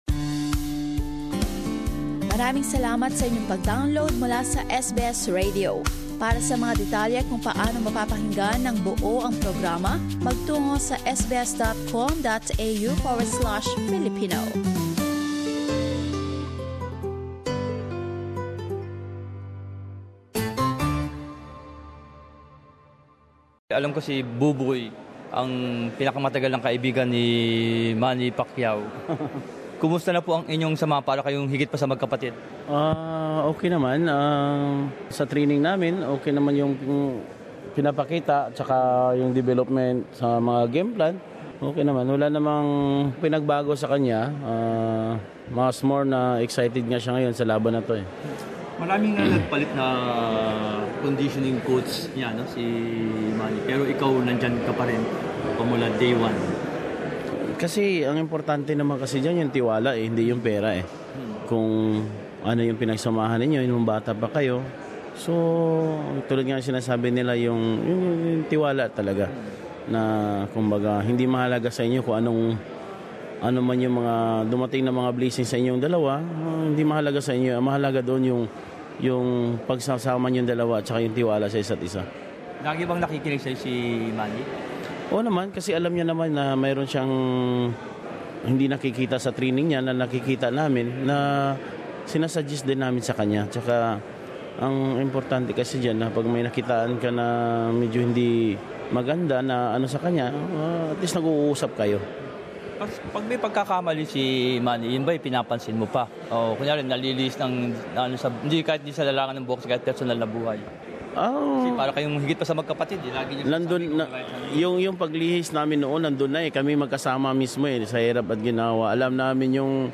Gaano nga ba katatag ang kanilang mga pinagsamahan? Narito ang maikling panayam